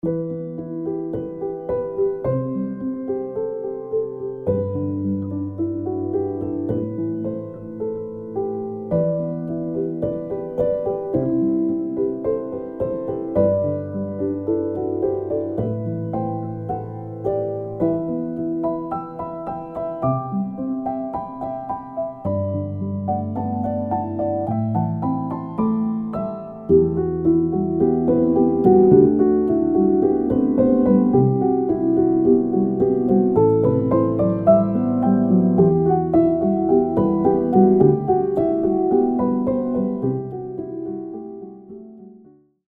Includes: 37 cinematic piano loops